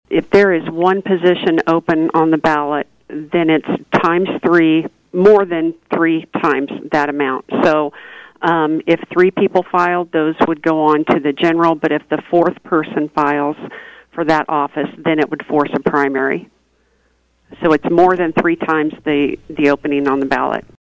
On KVOE’s Newsmaker 2 segment Tuesday, County Clerk and Election Officer Tammy Vopat said none of the positions for City Commission, City Council, mayor or school board currently have enough candidates to trigger a primary — which would be Aug. 1 if things change. There is typically some confusion about how the primary field is set, and Vopat clarified that procedure during the interview.